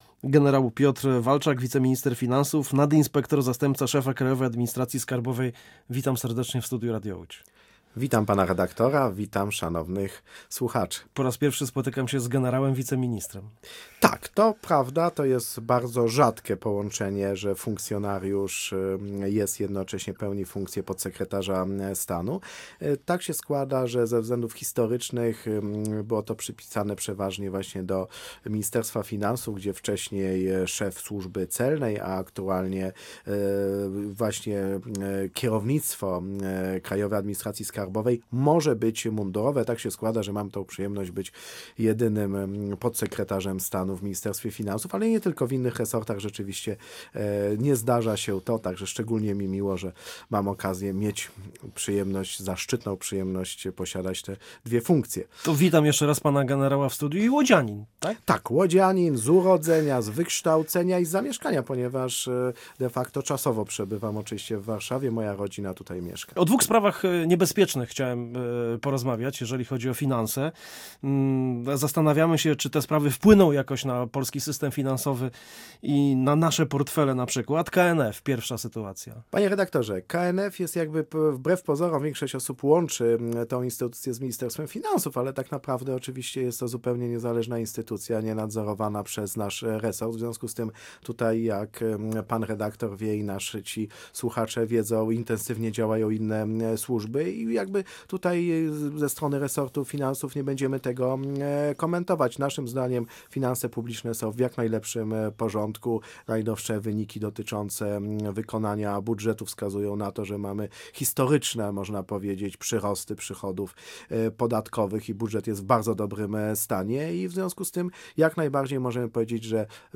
Posłuchaj całej rozmowy z wiceministrem finansów Piotrem Walczakiem: Nazwa Plik Autor Rozmowa z Piotrem Walczakiem, wiceministrem finansów audio (m4a) audio (oga) Sytuacja jest nieco bardziej skomplikowana, jeśli chodzi o stan wojenny na Ukrainie.